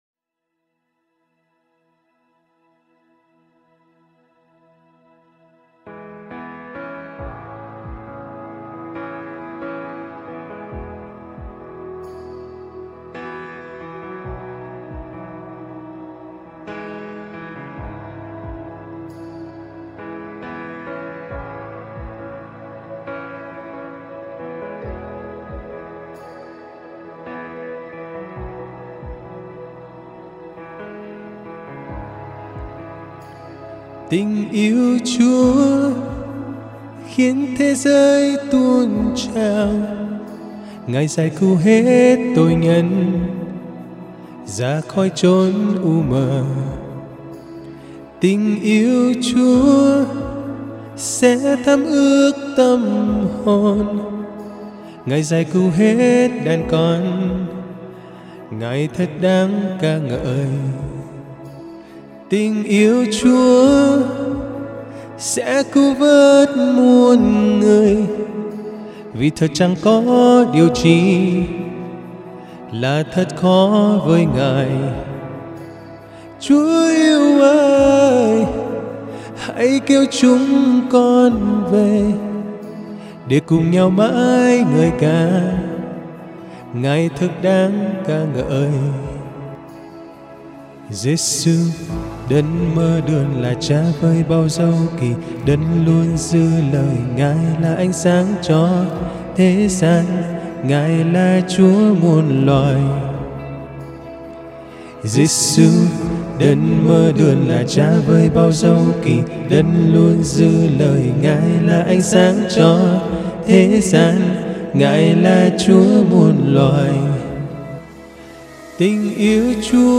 và hát ca ngợi thờ phượng CHÚA.